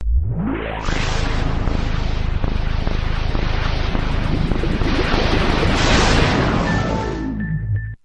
XBOX (2001) Startup.wav